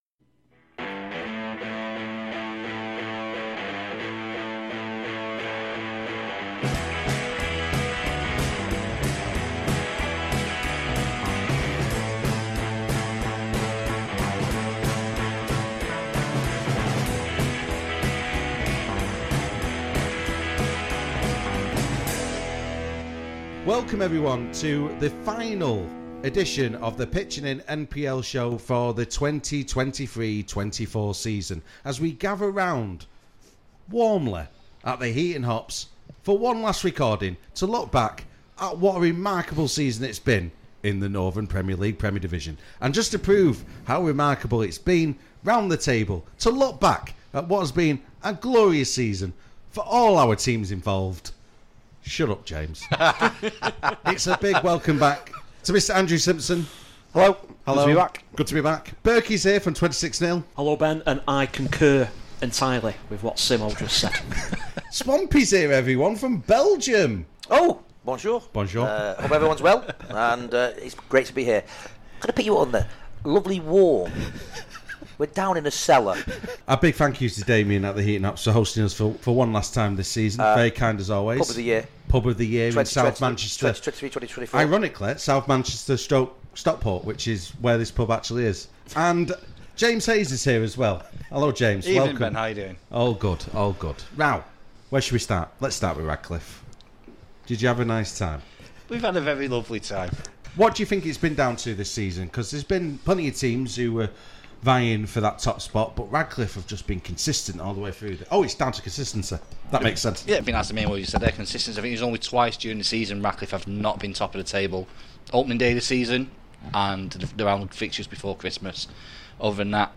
This programme was recorded at the Heaton Hops on Wednesday 15th May 2024.